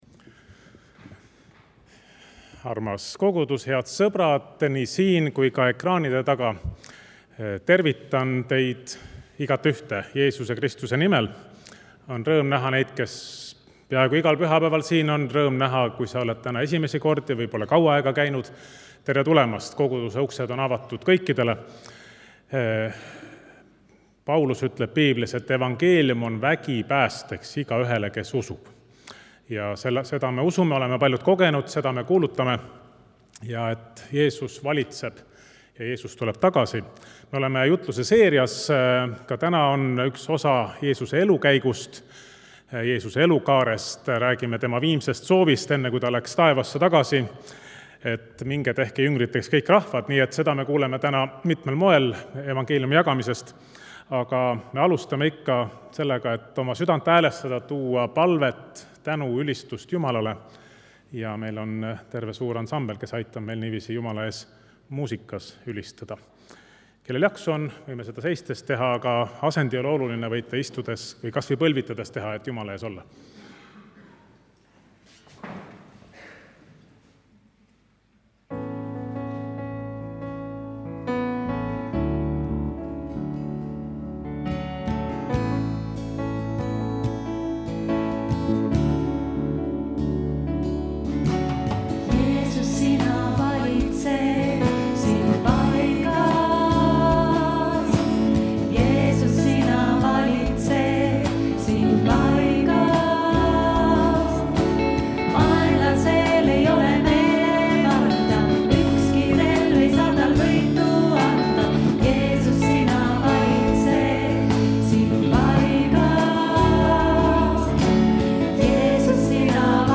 Muusika: Koguduse bänd